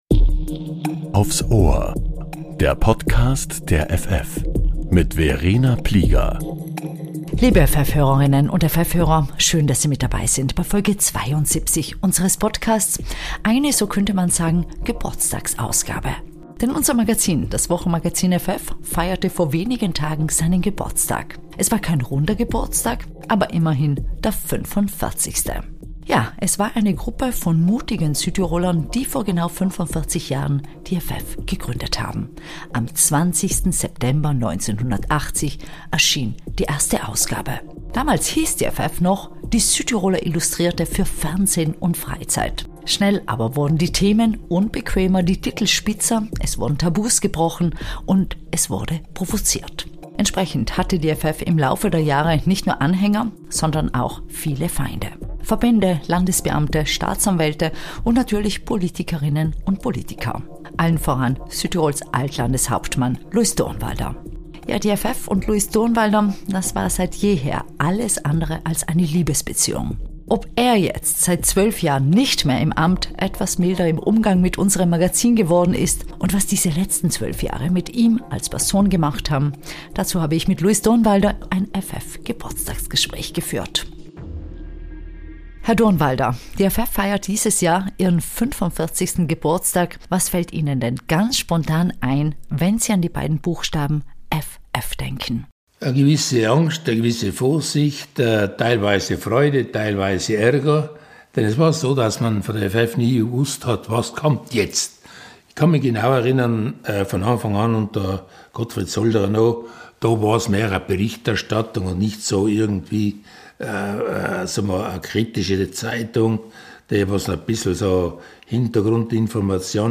Die ff wird 45 und spricht mit Alt-Landeshauptmann Luis Durnwalder, der mit unserem Magazin die wohl härtesten Kämpfe ausgetragen hat.